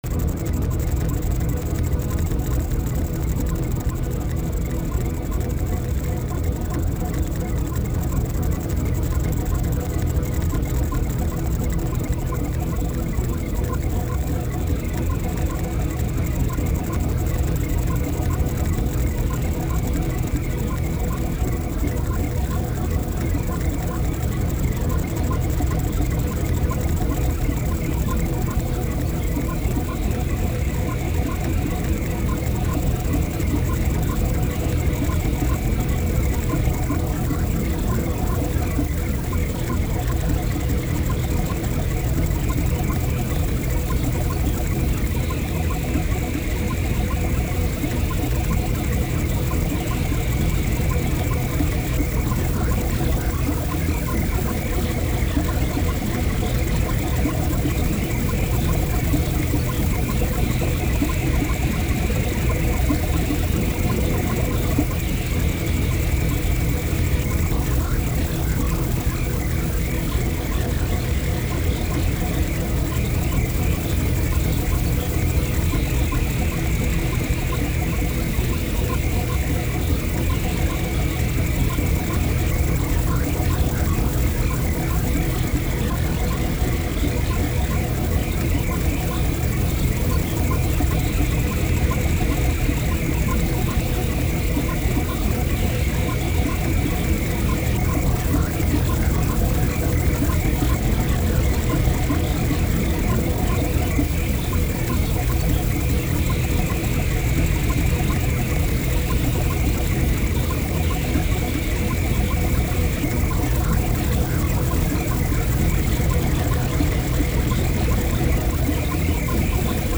The Second Largest marine geyser on Earth, La Bufadora is a blowhole and tourist attracion located on the Punta Banda Peninsula in Baja California, Mexico, about 17 miles south of Ensenada.